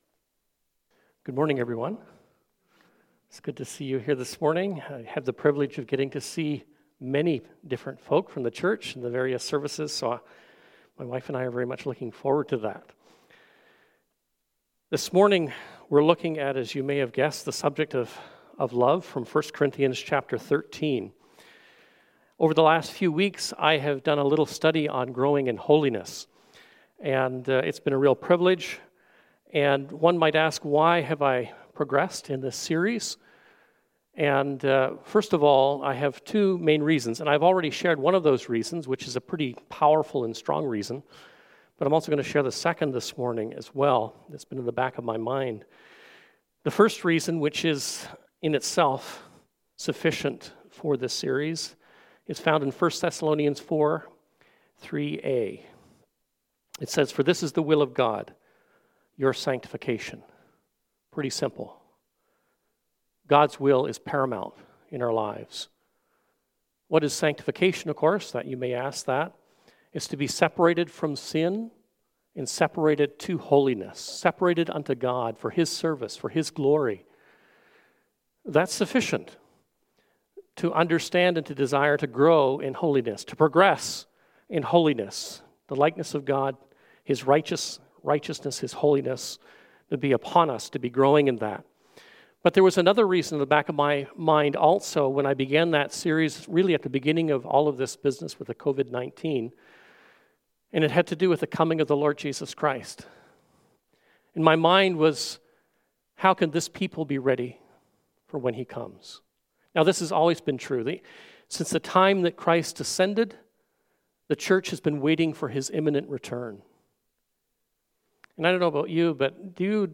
Sermons - Christ Community Church